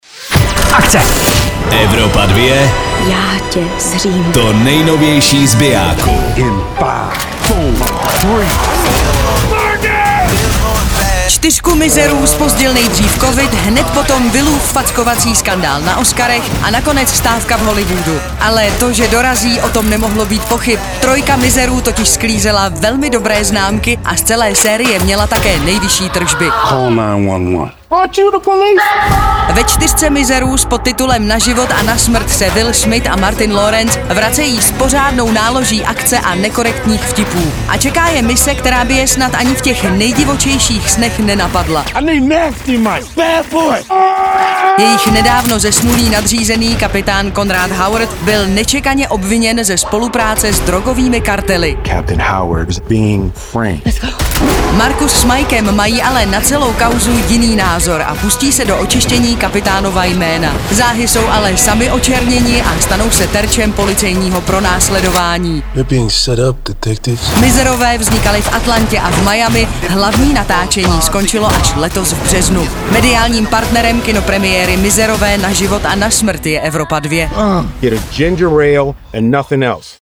filmový trailer